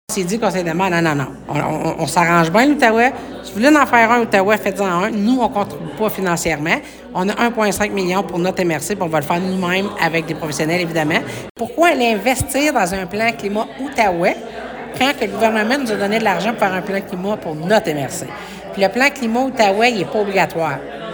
La MRC de La Vallée-de-la-Gatineau (MRCVG) refuse de participer à l’élaboration d’un plan climat à l’échelle de la région de l’Outaouais. La préfète Chantal Lamarche, explique que la MRCVG a reçu en 2024 plus d’un million de dollars pour produire un plan d’action climat pour son propre territoire , à la demande du gouvernement du Québec.